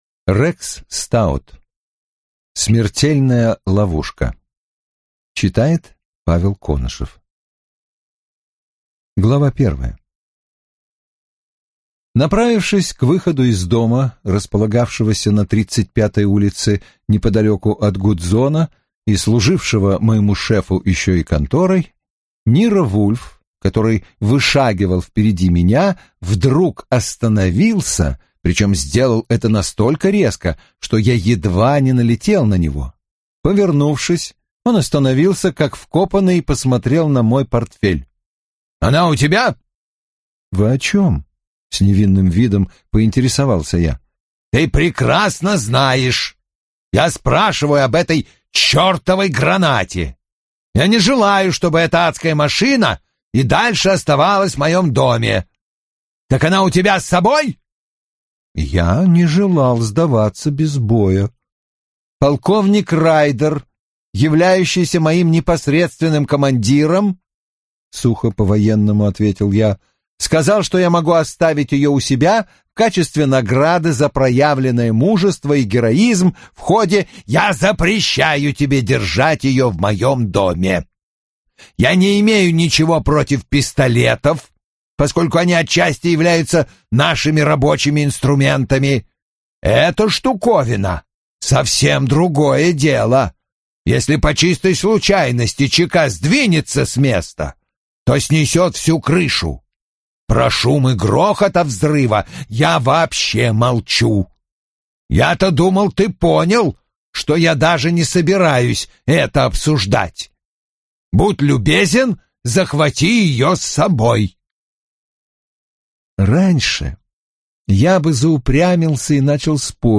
Аудиокнига Смертельная ловушка | Библиотека аудиокниг